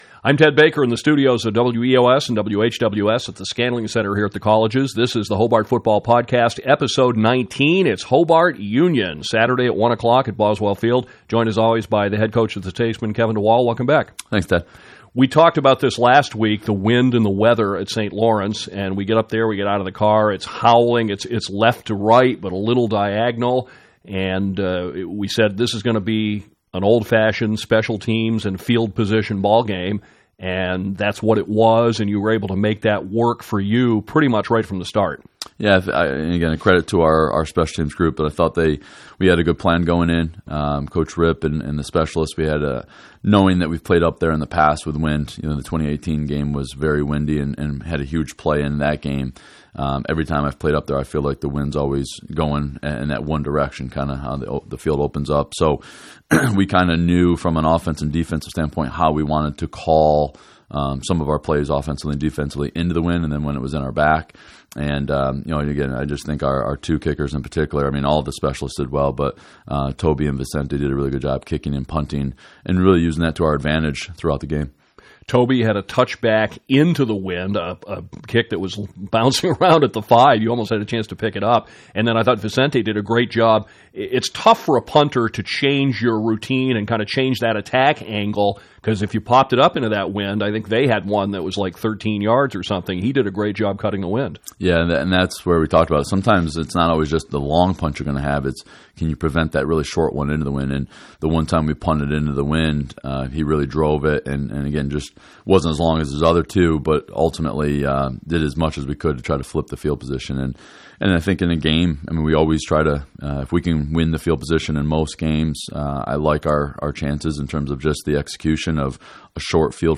The Hobart Football Podcast is recorded weekly during the season.